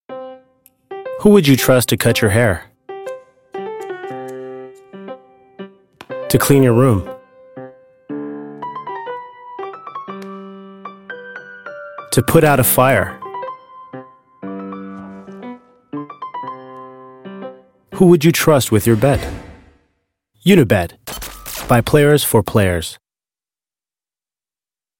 friendly, open, guy-next-door, confident, believable, straight-forward, serious, authoritative, warm,gravelly,appealing,smoky, rough, encouraging, rap
Sprechprobe: Sonstiges (Muttersprache):